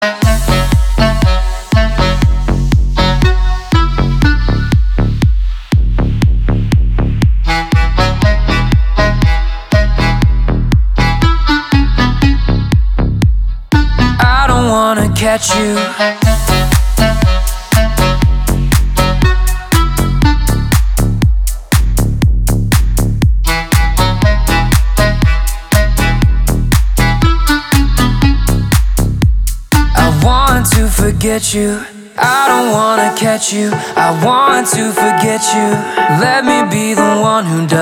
мужской голос
громкие
зажигательные
dance
house